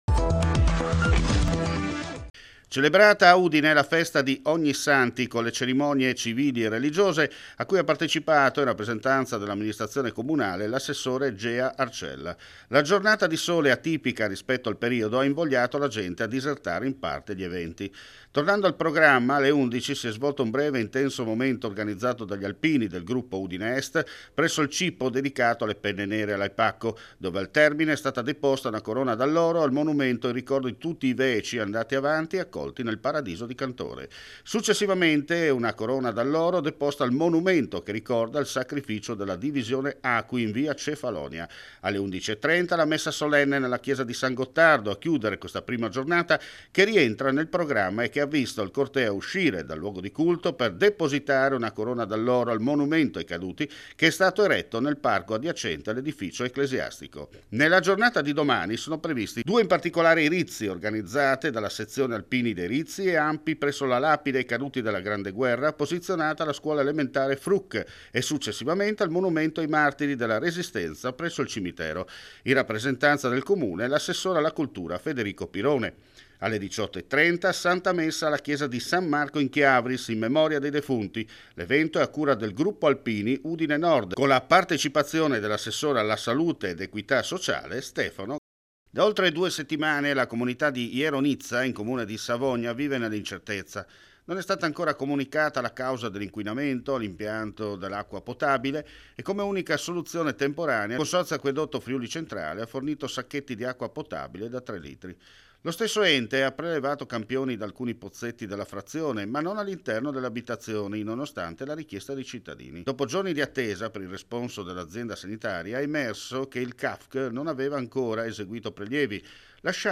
FRIULITV GIORNALE RADIO: LE ULTIME AUDIONOTIZIE DAL FRIULI VENEZIA GIULIA – FriuliTv Networking